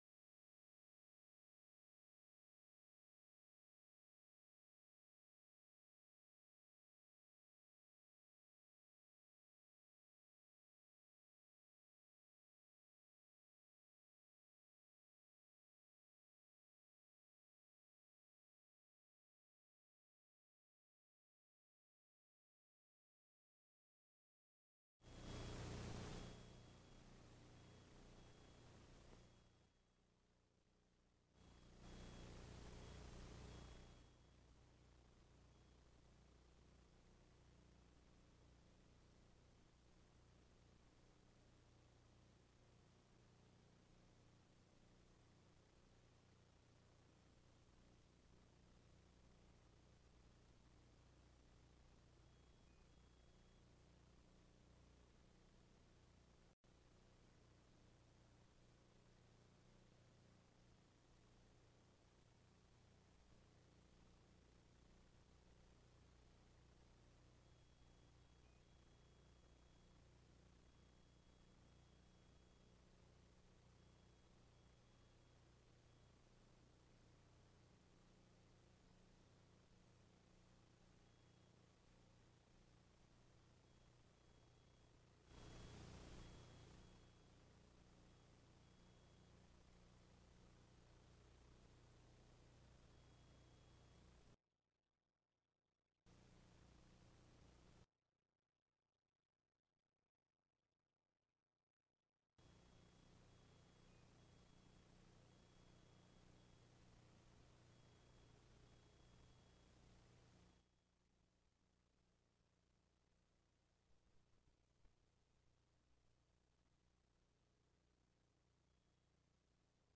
Press point by NATO Secretary General Jens Stoltenberg at ASAN Institute for Policy Studies, Seoul, Republic of Korea
(As delivered)